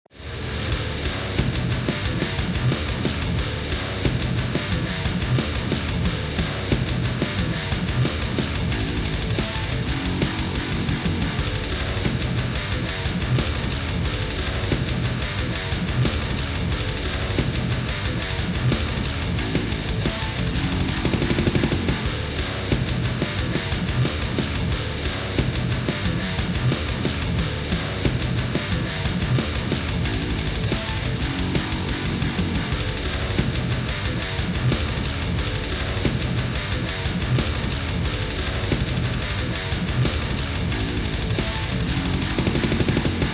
So kann ich mal fix Gitarre und Bass einspielen, MIDI-Drums basteln und hab soliden Sound.
Ist spieltechnisch nichts anspruchsvolles und nur ein Riff. Aber hey...ist Metal! Und so langsam bin ich mit dem Sound zufrieden, denke ich.